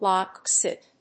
/lάk sít(米国英語), lˈɔk sít(英国英語)/